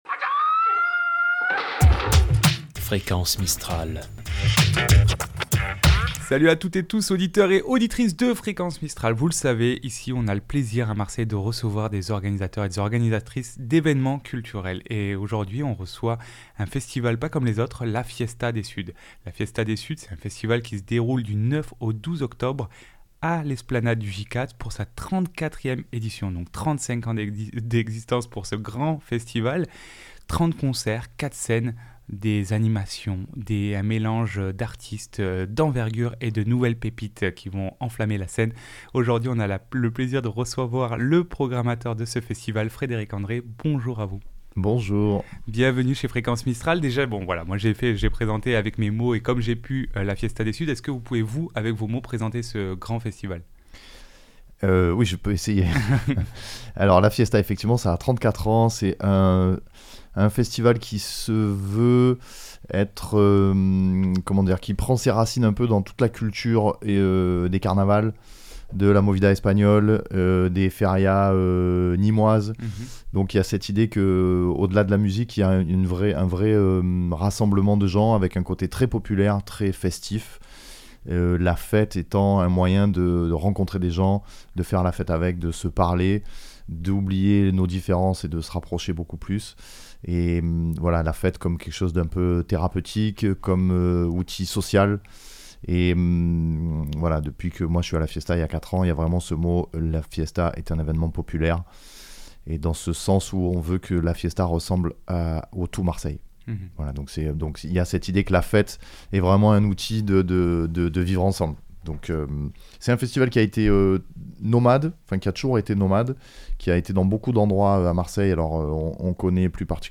Mercredi 1 Octobre 2025 Événement majeur réunissant icônes internationales, artistes d'envergure et pépites en pleine explosion, la Fiesta des Suds est de retour du 9 au 12 octobre, pour sa 34è édition! Rencontre